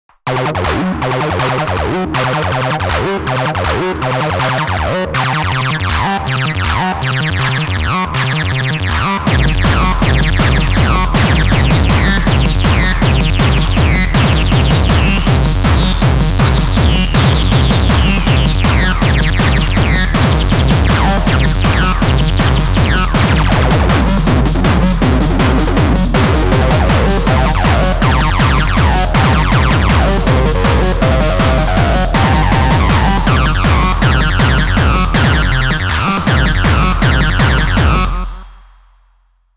raw = kicks & acid heaven